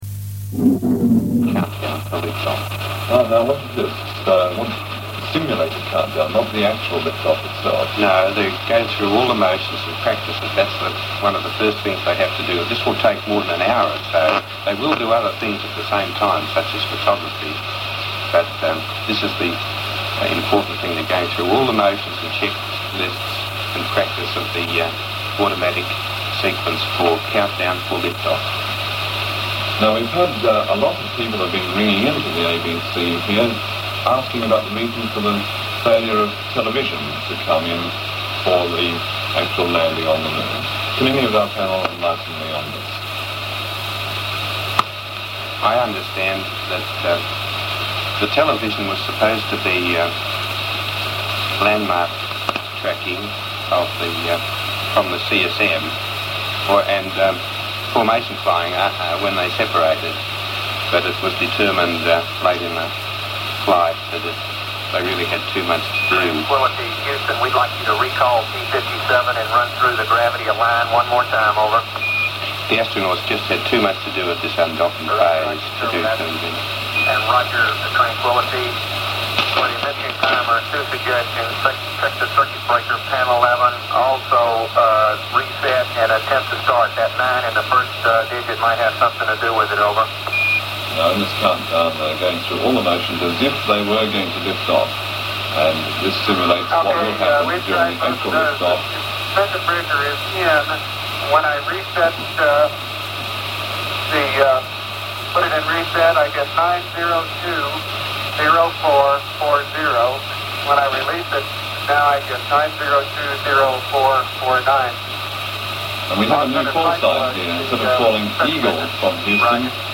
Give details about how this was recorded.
These recordings were made by an unknown person on Norfolk Island of the ABC's commentary of the Apollo 11 mission. This is evident from the frequent lightning discharges. The monophonic recordings were made on five 62 metre tapes and one 150 metre tape recorded at 4.75 cm/s.